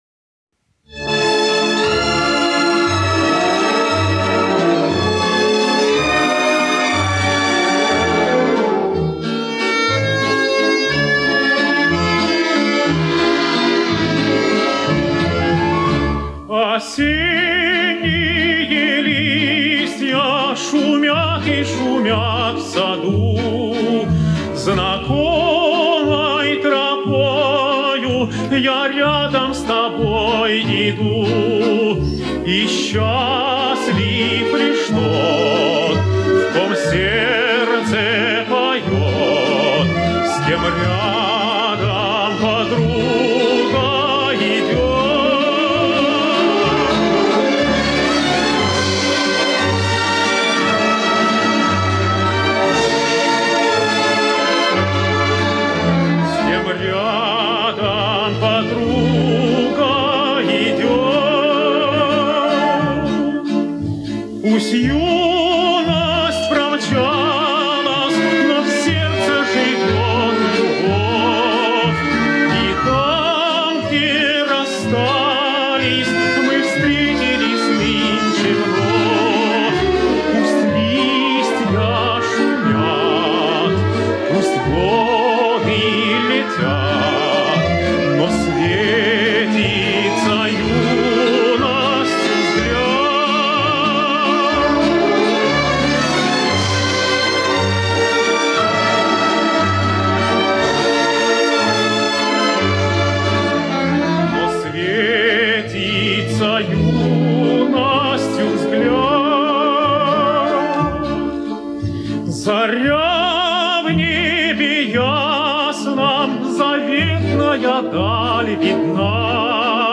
А Оркестр....?